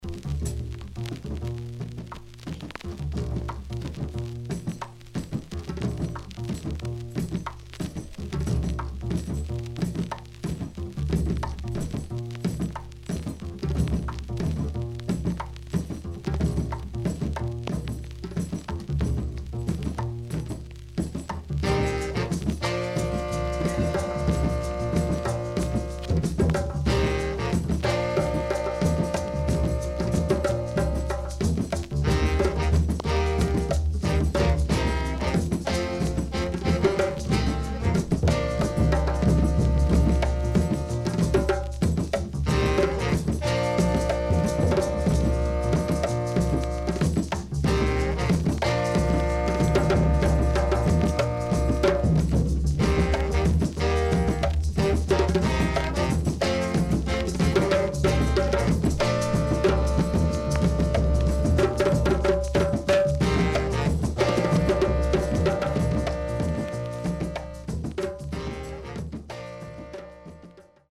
HOME > REGGAE / ROOTS  >  RECOMMEND 70's
SIDE A:所々チリノイズがあり、少しプチノイズ入ります。